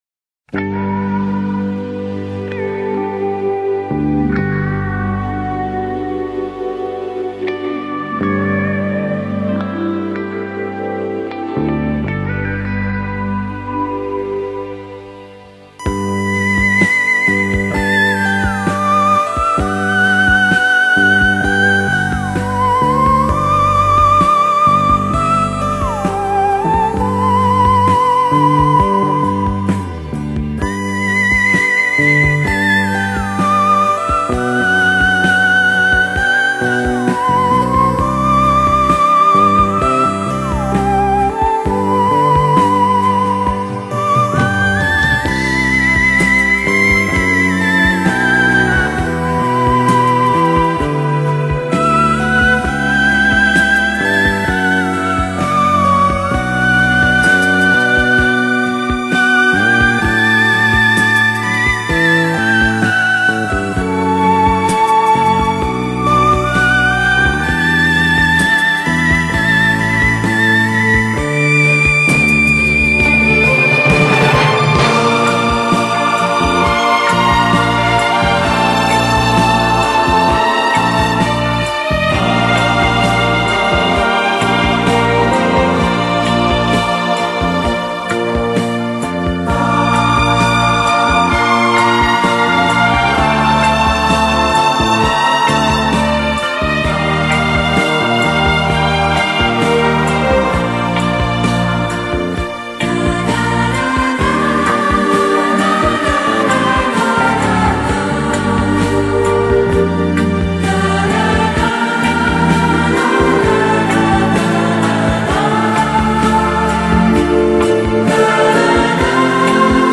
他以清新、明快的音乐